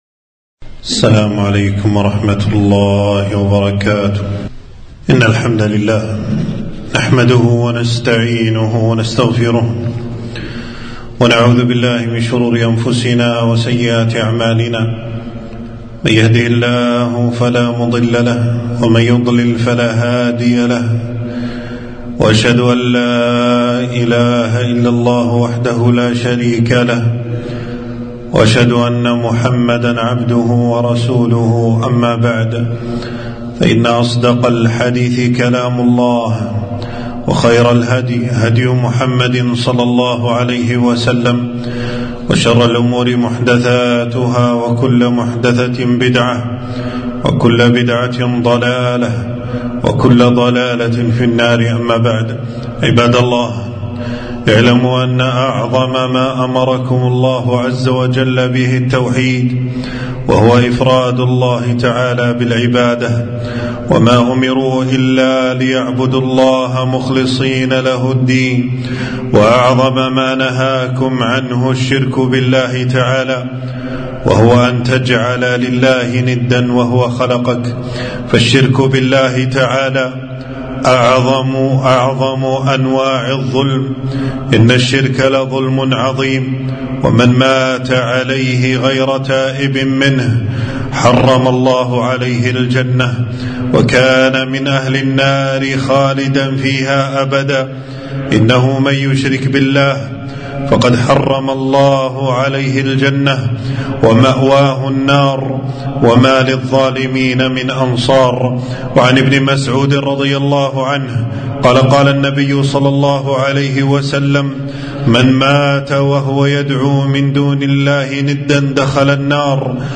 خطبة - إن الشرك لظلم عظيم